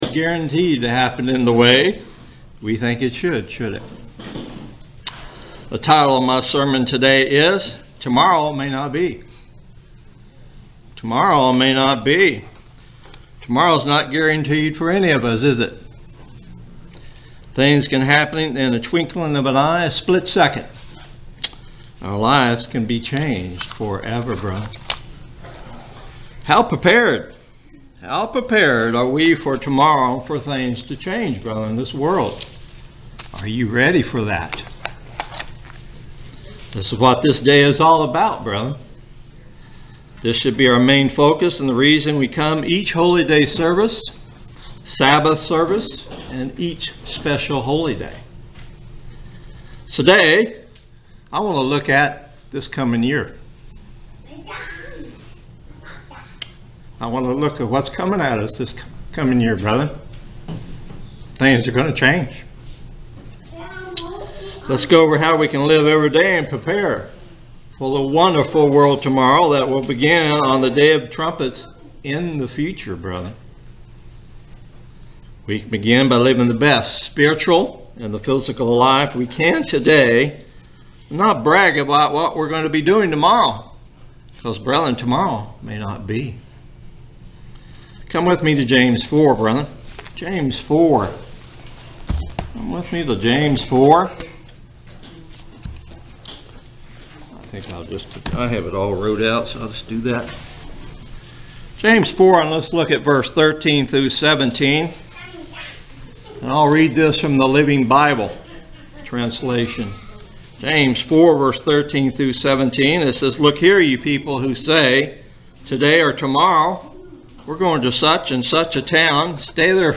Given in Ocala, FL